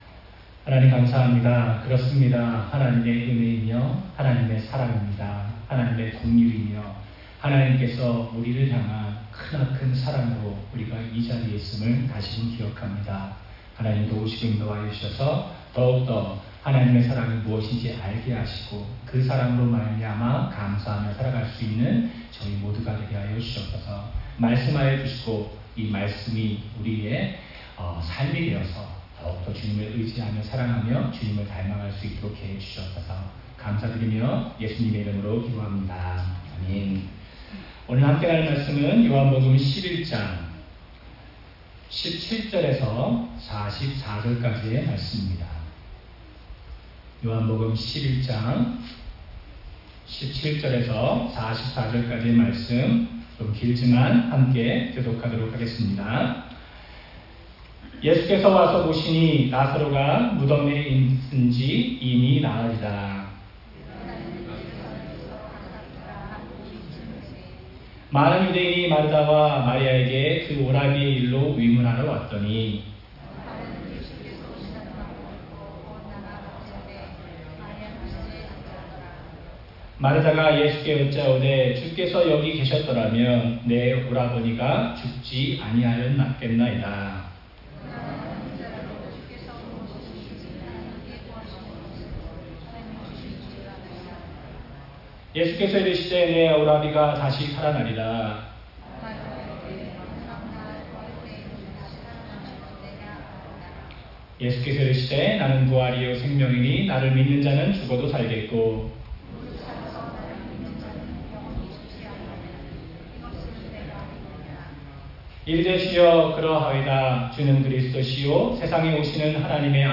주일 설교
9월-15일-주일-설교.mp3